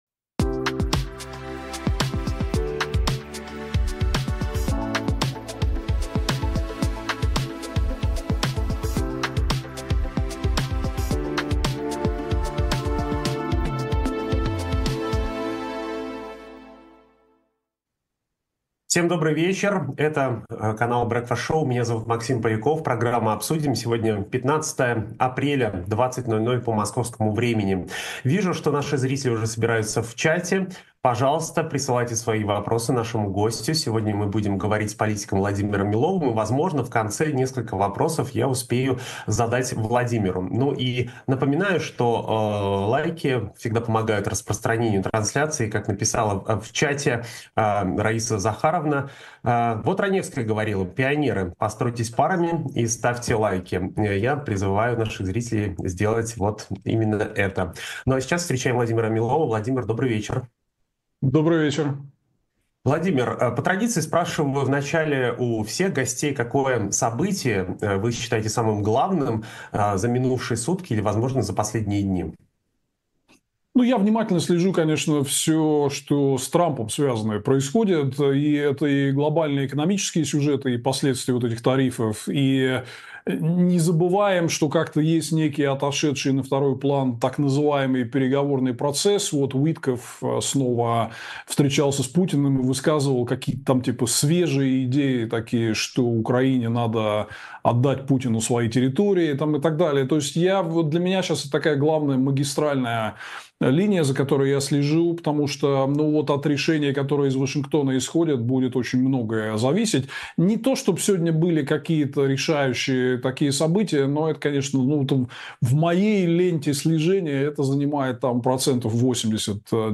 Гость сегодняшнего выпуска — политик Владимир Милов. С ним мы обсудим, почему США отказались осудить удар по Сумам, возможен ли в РФ кризис этой осенью и чем Лаврова пугают чиновники «либеральных взглядов».